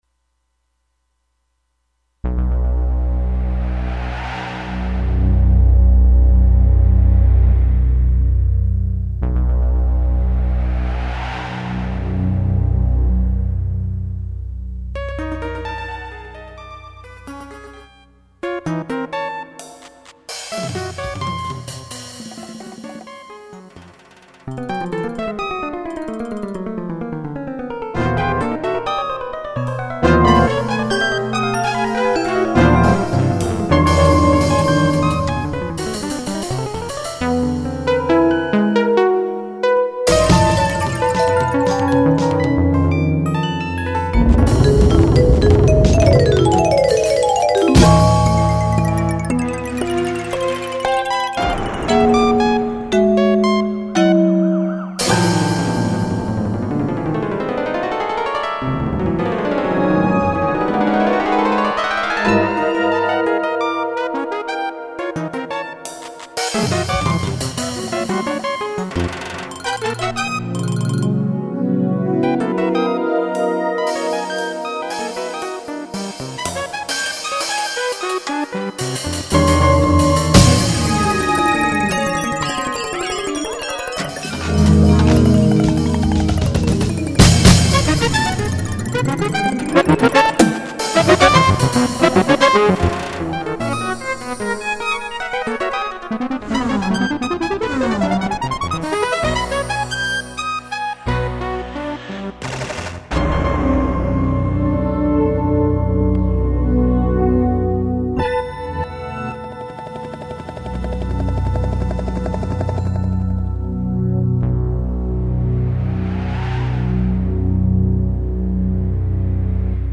This fanfare is a computer sequence developed during an experimental period working with that medium.
It has an anti-war theme that has appeared in many Exis Jaxn compositions. The piece was composed with an inexpensive sequencer called Ballade on a 486 loaded with DOS 6.0 and the LAPC-1 sound card.